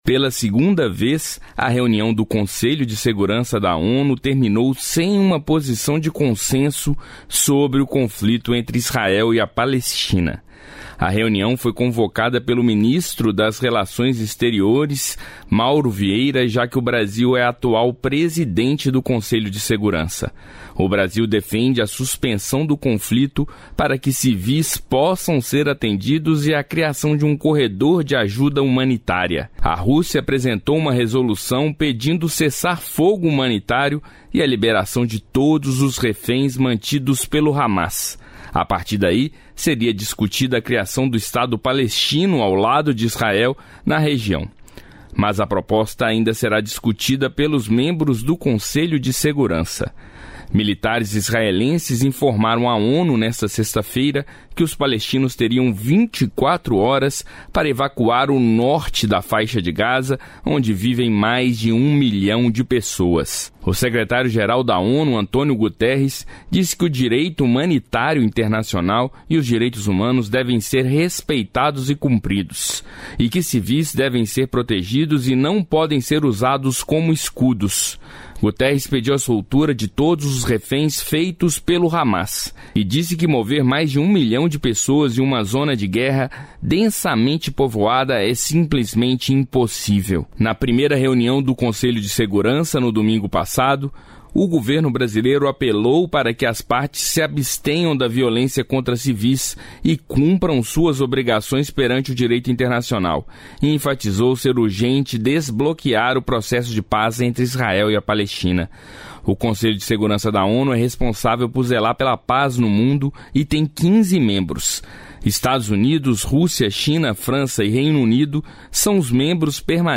Repórter Rádio Nacional Conflito no Oriente Médio Faixa de Gaza ONU sexta-feira